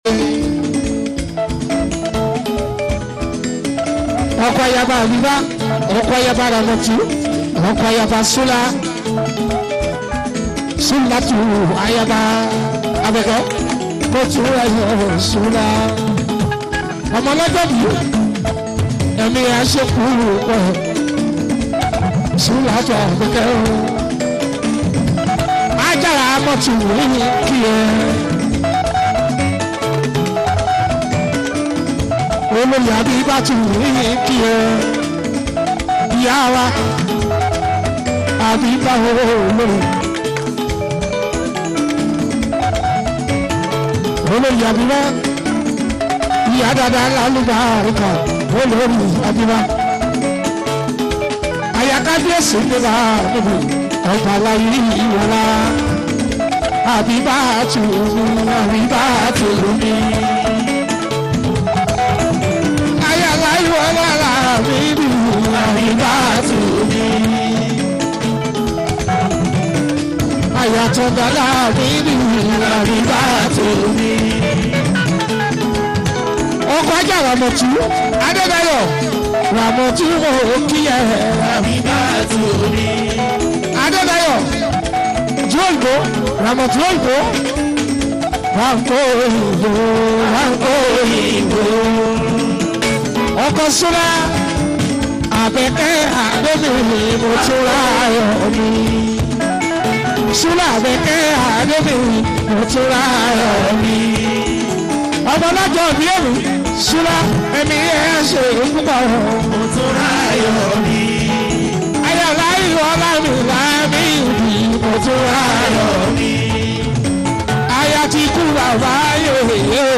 Nigerian Yoruba Fuji track